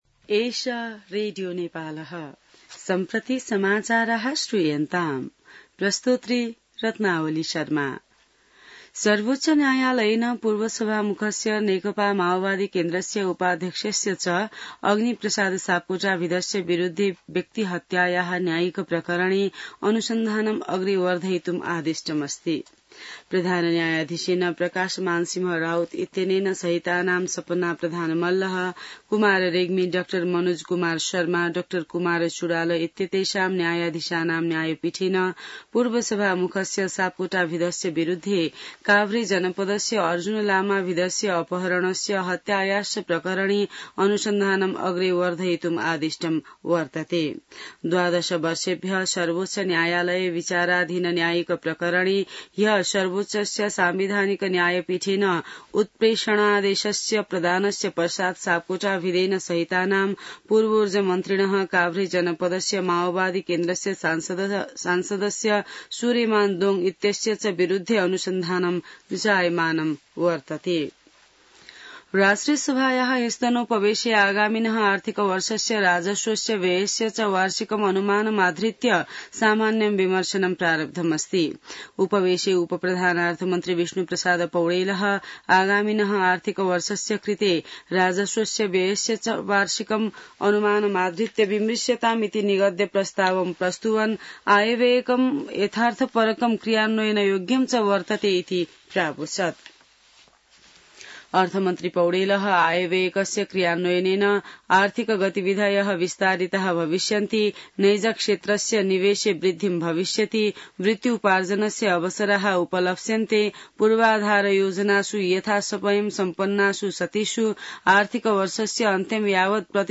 संस्कृत समाचार : २२ जेठ , २०८२